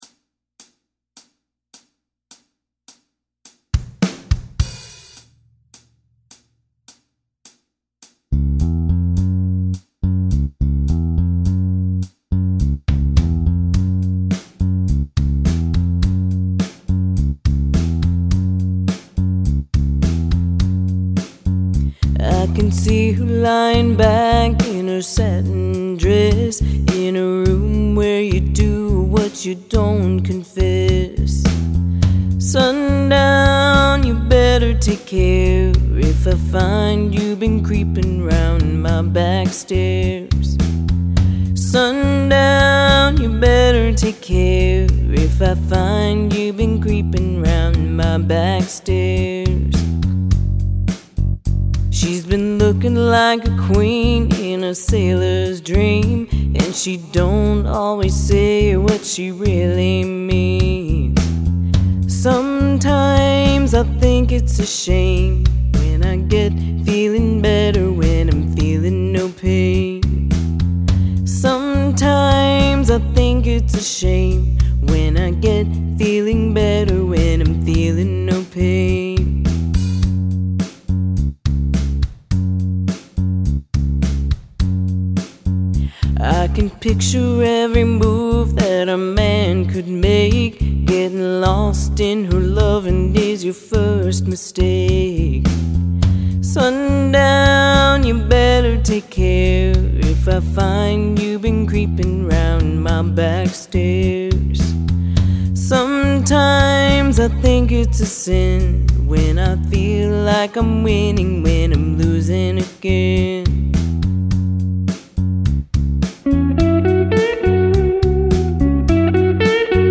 “Sundown” Gordon Lightfoot - Easy Guitar Lesson
All you need to play through the easy version of this song is four chords and one strumming pattern.